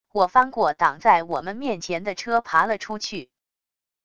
我翻过挡在我们面前的车爬了出去wav音频生成系统WAV Audio Player